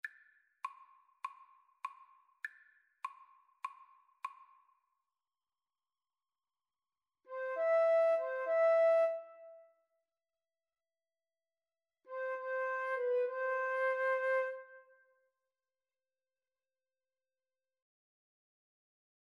Traditional Trad. Funga Alafia Flute Duet version
Flute 1Flute 2
Nigerian call and response welcome song.
4/4 (View more 4/4 Music)
C major (Sounding Pitch) (View more C major Music for Flute Duet )
With Energy